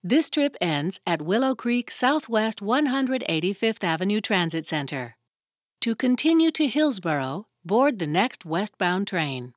Index of /cdn-files/documents/TRI-MET/Trimet transit anns/MAX_post1998/4_28_2009/16bit-11K files/